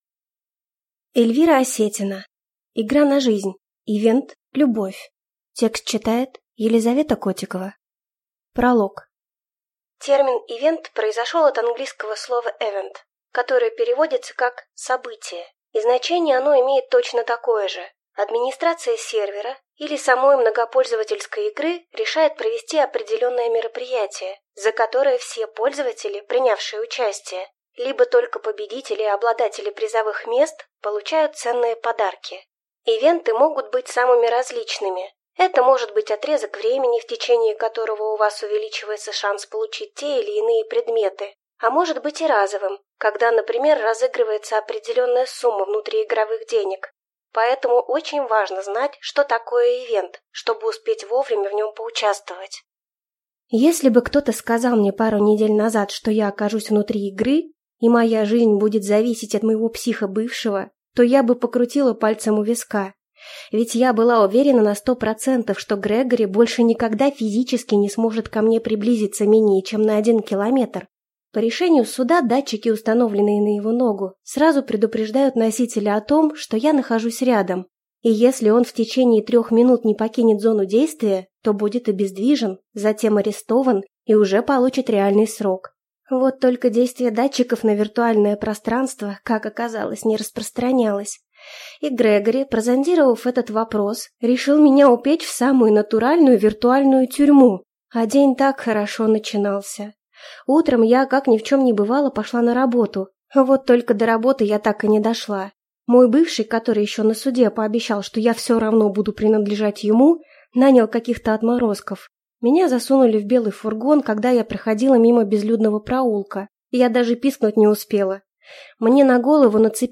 Аудиокнига Игра на жизнь. Ивент – любовь | Библиотека аудиокниг